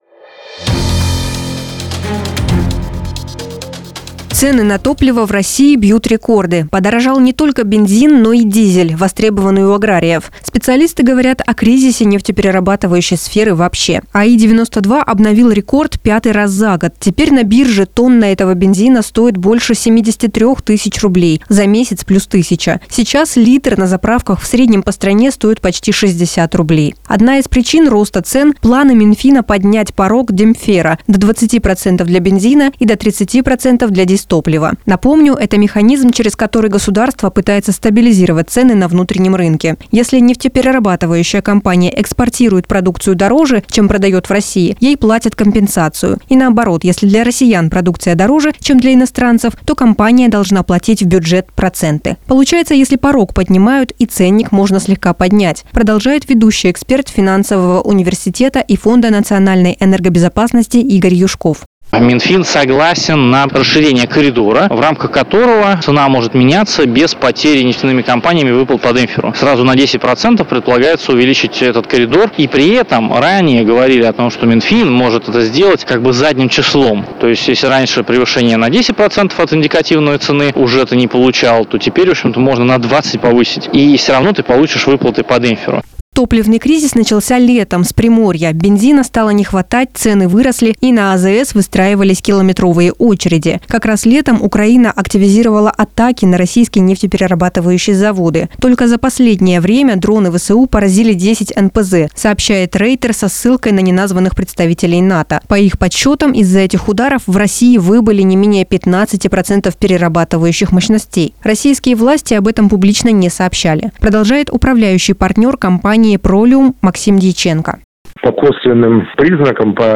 Сюжет на Business FM (Бизнес ФМ) Барнаул